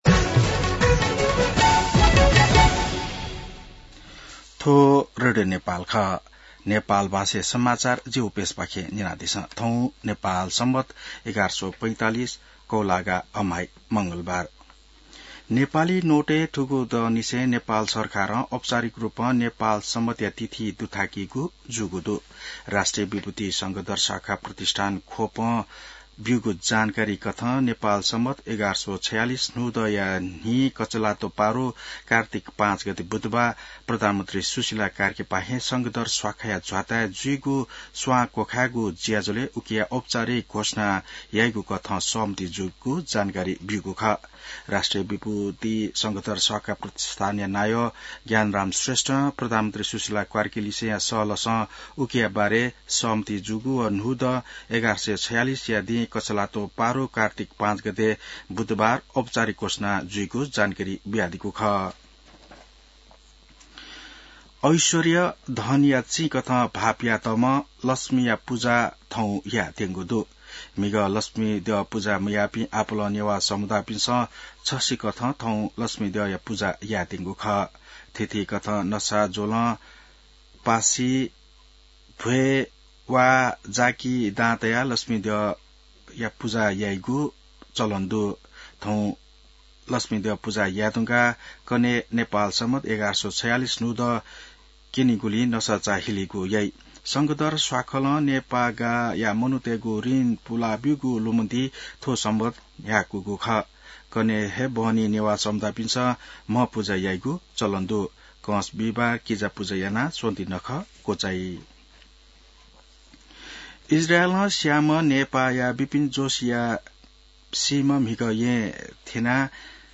नेपाल भाषामा समाचार : ४ कार्तिक , २०८२